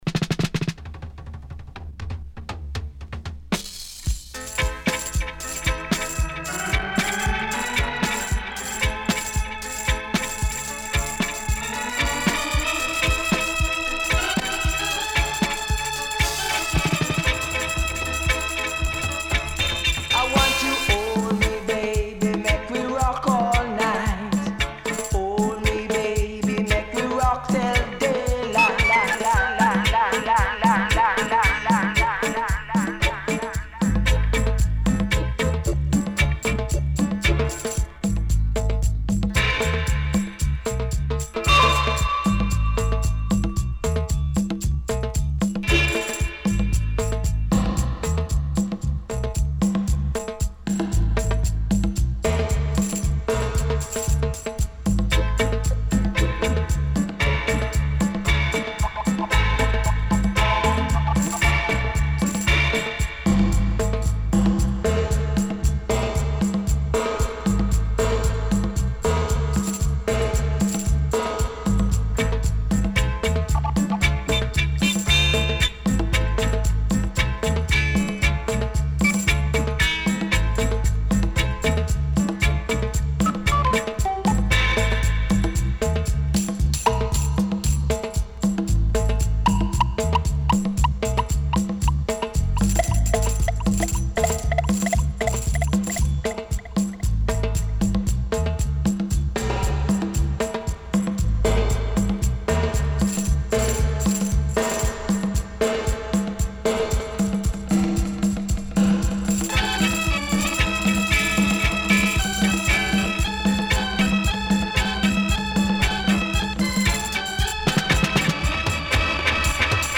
HOME > DUB